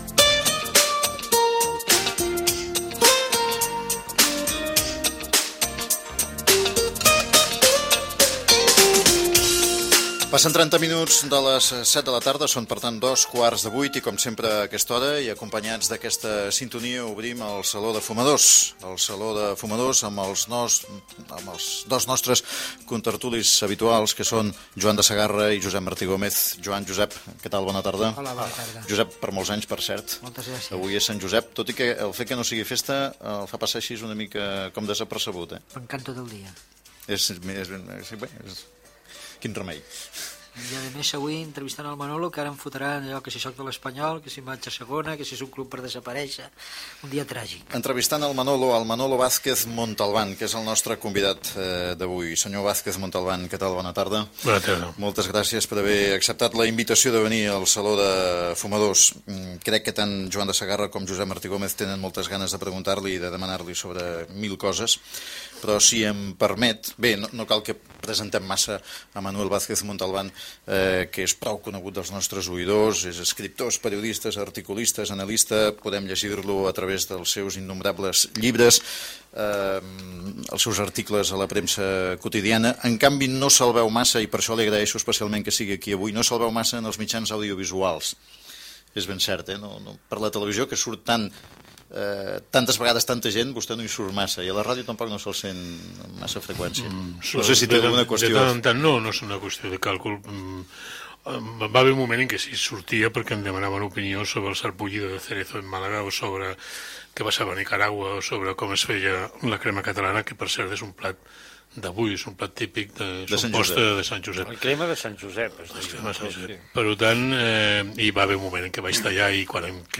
7a739f44011c1a204f8b9c7ffb3b9190cd9ad9f2.mp3 Títol Ràdio Barcelona Emissora Ràdio Barcelona Cadena SER Titularitat Privada estatal Nom programa El balcó (SER) Descripció Secció "Saló de fumadors". Hora presentació dels dos contertulis i de l'invitat, l'escriptor Manuel Vázquez Montalbán.
Info-entreteniment